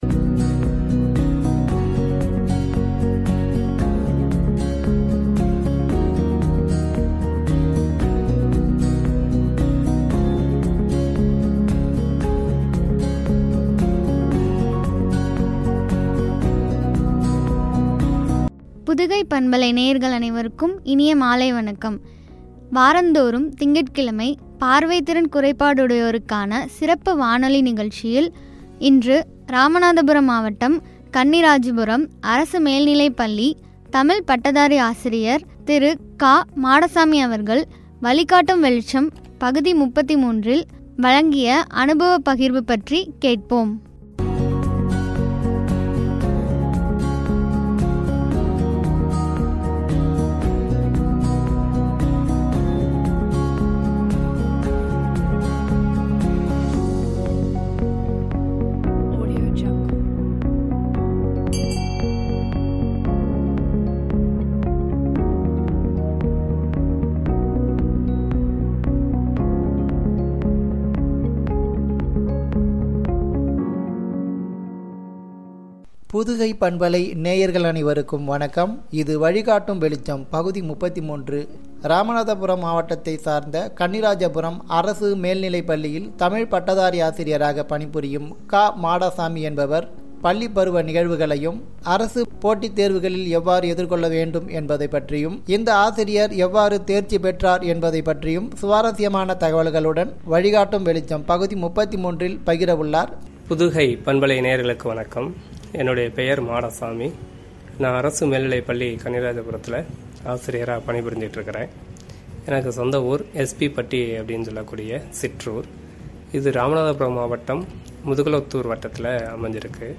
குறித்து வழங்கிய உரை.